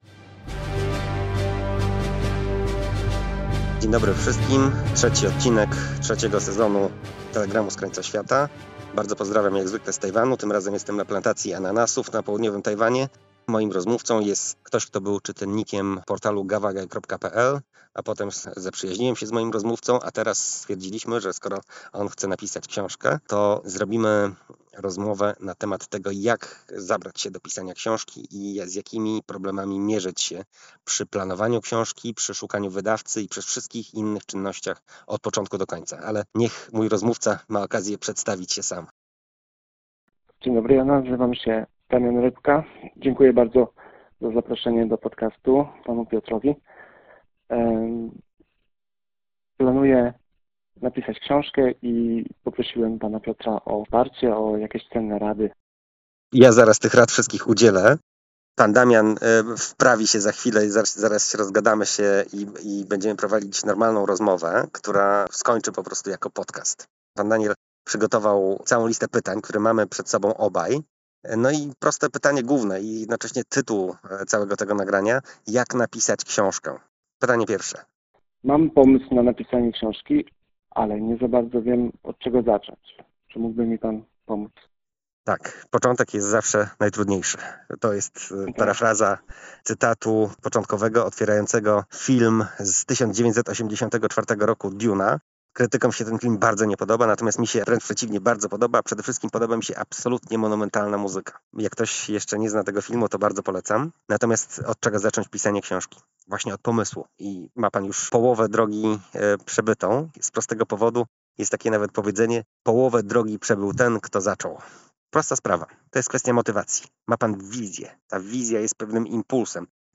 Rozdziały książek: 0 Główna omawiana książka: Prawidła geopolitycznej gry o przetrwanie Nasza przyszłość w czasach niepewności Prezentacja Kup od autora Pocztówka z Tajwanu Miejsce realizacji nagrania Platforma widokowa w dolinie, w której środku ulokowane jest plemię ludu aborygeńskiego Bunun.